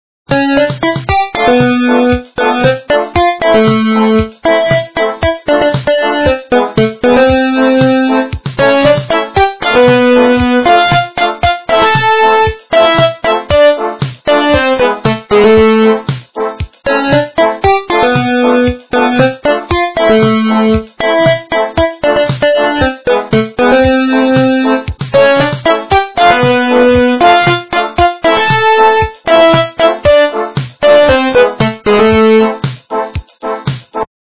- Шансон
качество понижено и присутствуют гудки